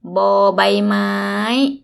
– boor – baii – / maii